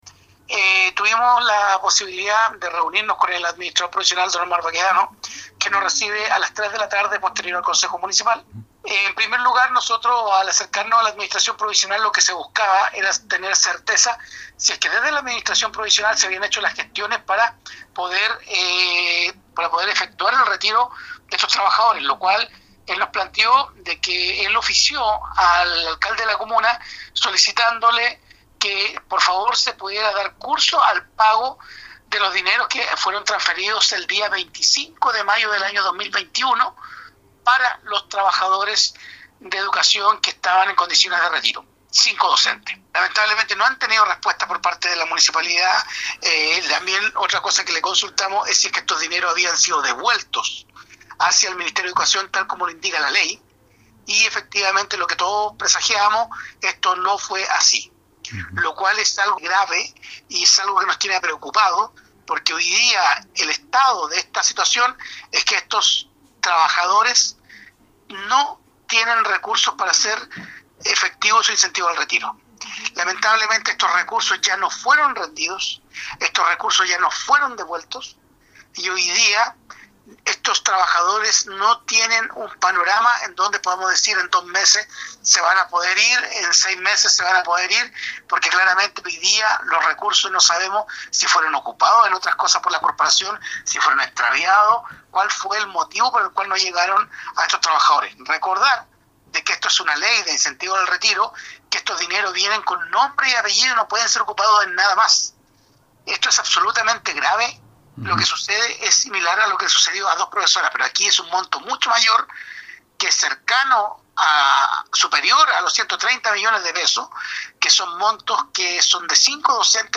A su vez, el concejal Andrés Ibáñez se mostró afectado por la falta de respuestas y diligencias de parte del municipio y de sus directivos de aquellos acuerdos adoptados por el concejo y que pedían claridad acerca del destino de este millonario monto.
31-CONCEJAL-ANDRES-IBANEZ.mp3